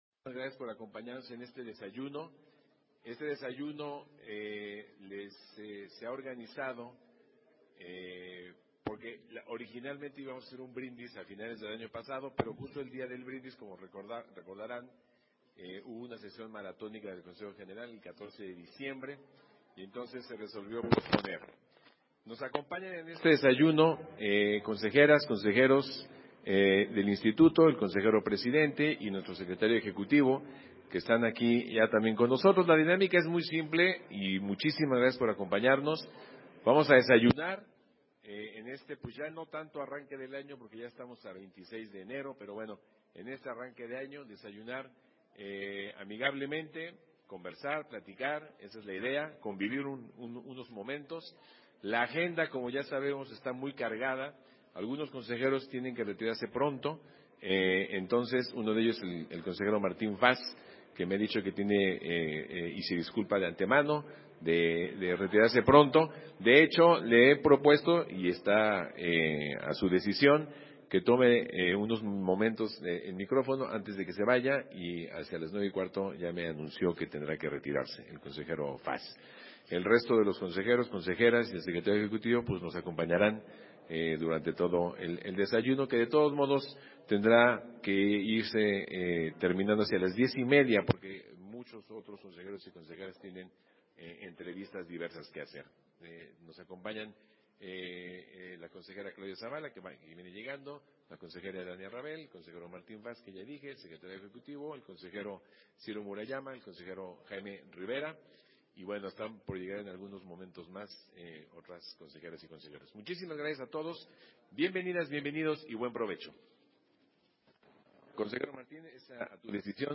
Versión estenográfica del encuentro que sostuvieron Consejeras y Consejeros Electorales del INE, con representantes de los medios de comunicación